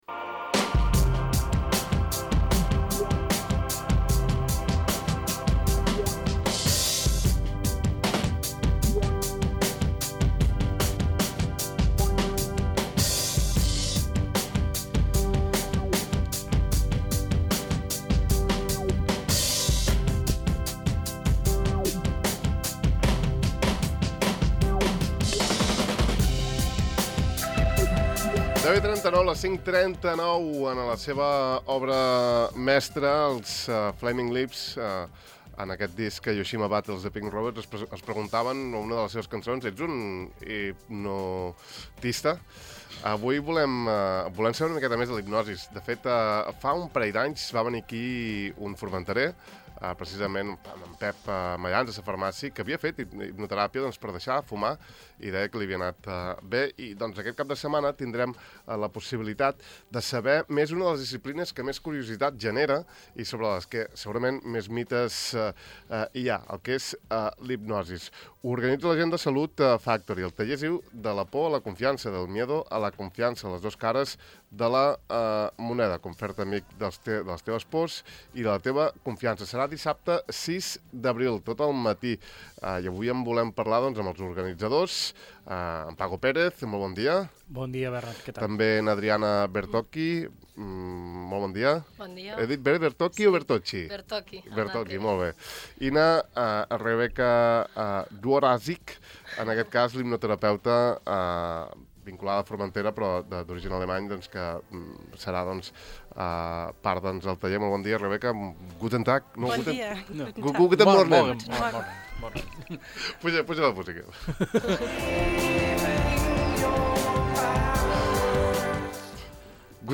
Avui hem parlat amb els tres d’aquesta disciplina i les seves avantatges amb ells tres.